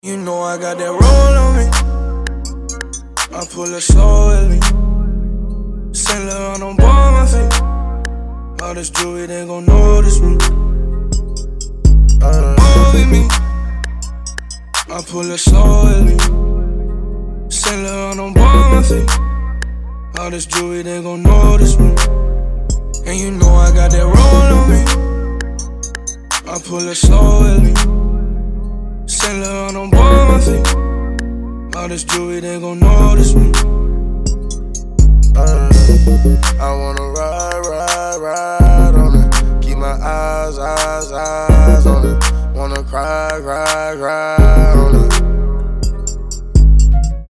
мужской вокал
лирика
Хип-хоп
спокойные